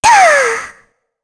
Shea-Vox_Attack4_kr.wav